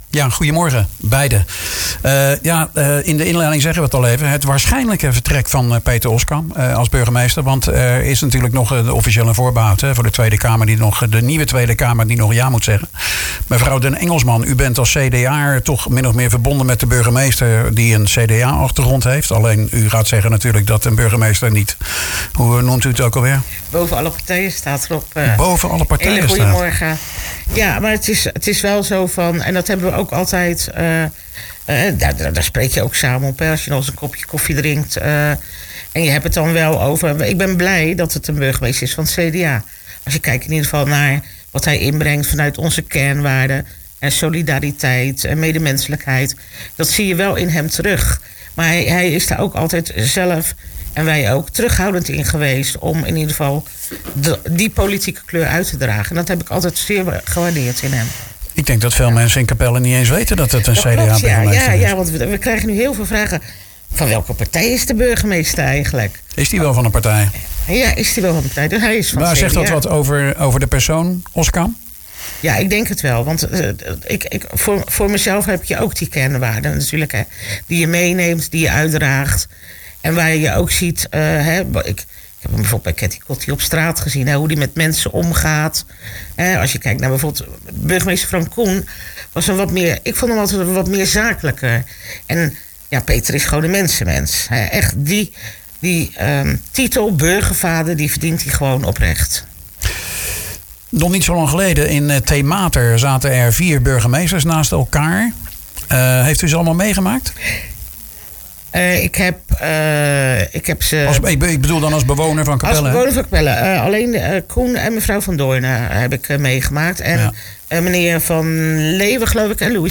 praat erover met Jolanda den Engelsman, raadslid voor het Capelse CDA en SGP-raadslid Pieter Weijers. Hoe hebben zij Oskam ervaren als burgemeester en als voorzitter van de gemeenteraad.